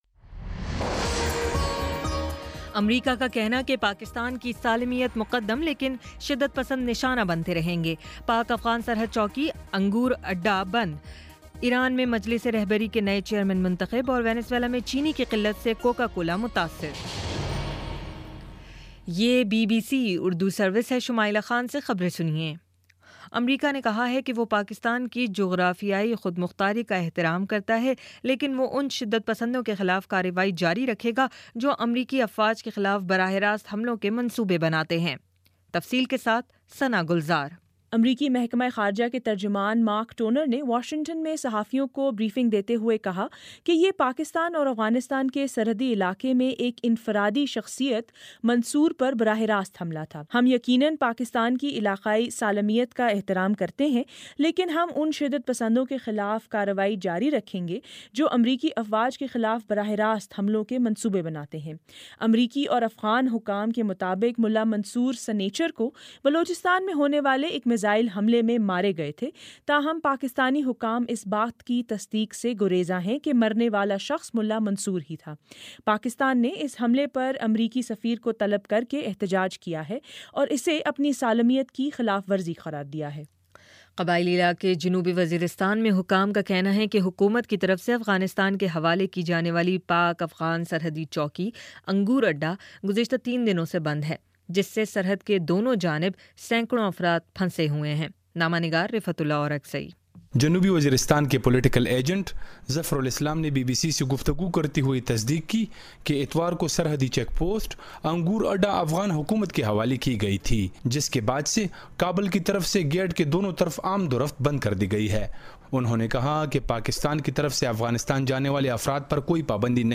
مئی 24 : شام پانچ بجے کا نیوز بُلیٹن